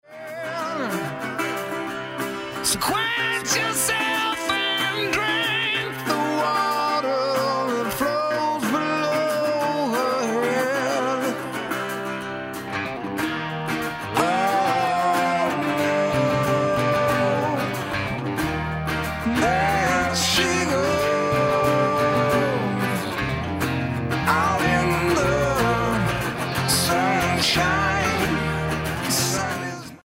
guitar
drums
bass